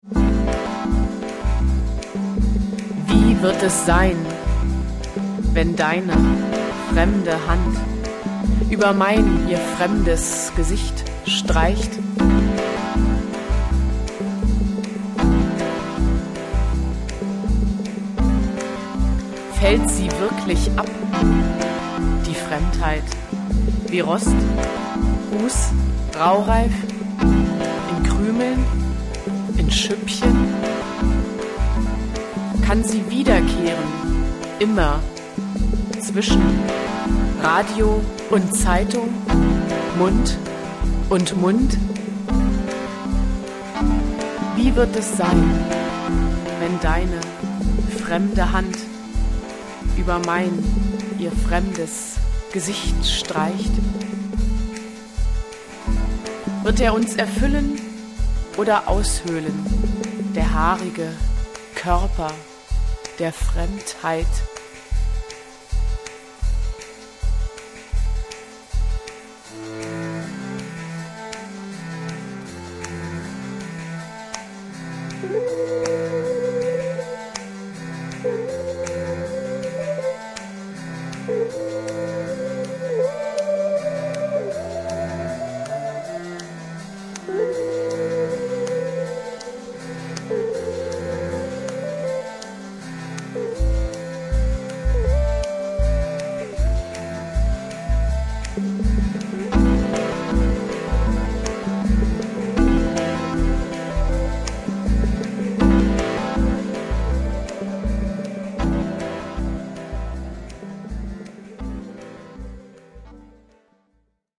Lyrik/Kurzprosa
Konzeptalbum